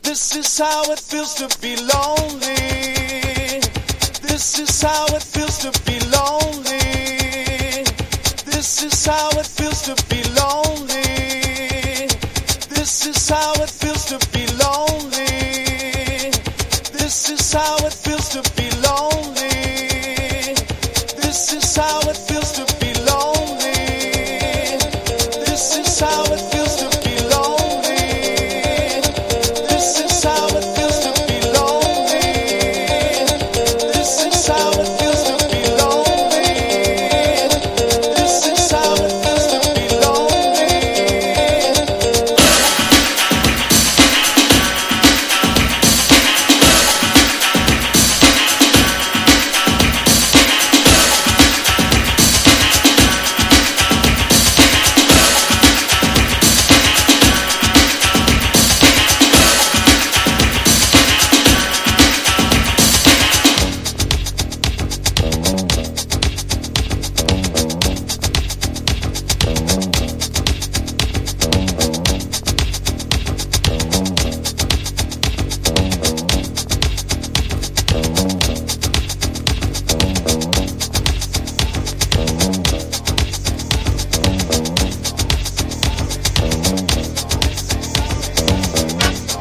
1. 90'S ROCK >
INDIE DANCE# DUB / LEFTFIELD